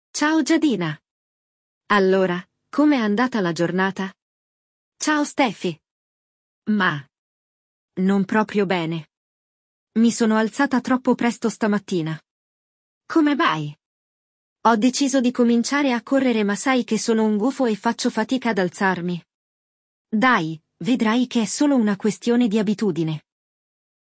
No episódio de hoje, vamos acompanhar uma conversa entre duas amigas: uma delas começou uma nova atividade, mas está com dificuldade de manter o hábito.
Il dialogo